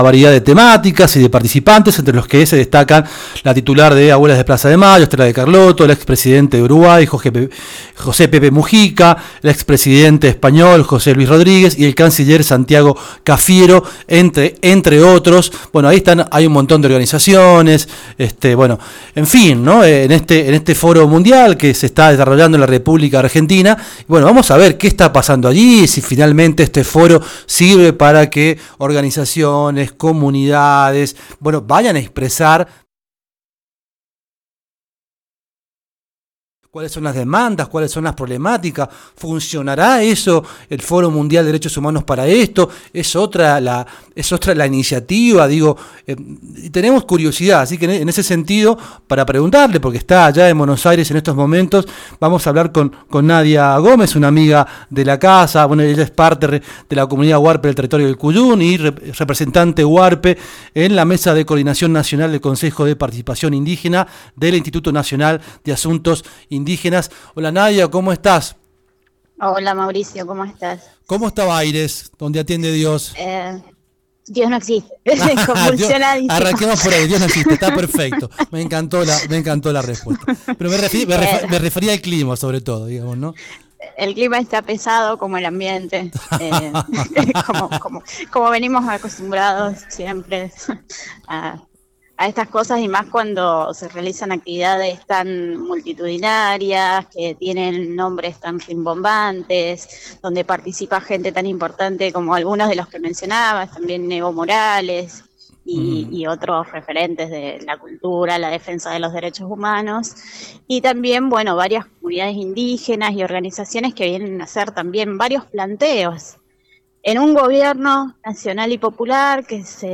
En ese contexto, Condado Radio conversó con